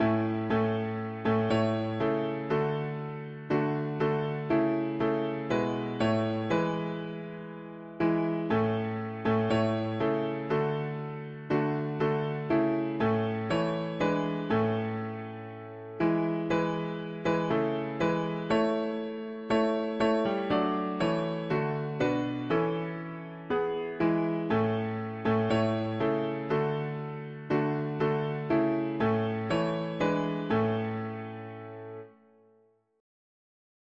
Let war be learned no longer, let strife a… english secular 4part
Key: A major